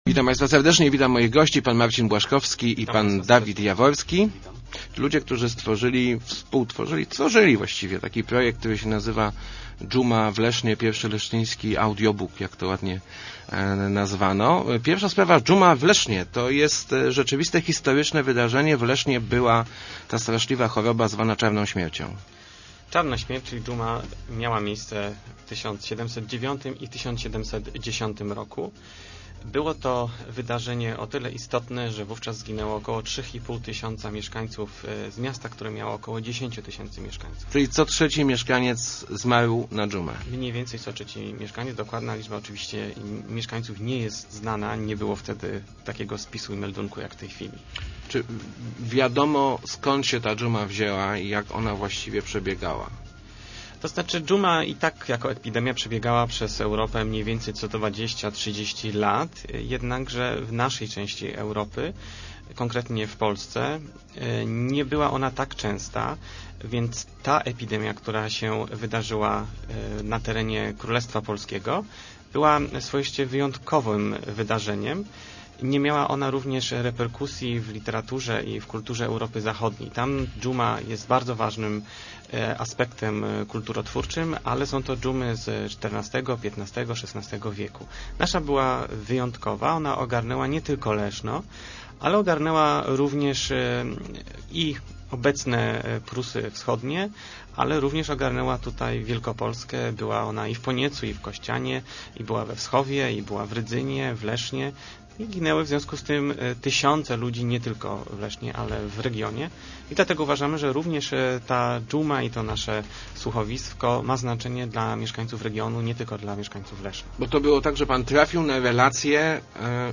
Start arrow Rozmowy Elki arrow Umarł co trzeci leszczynianin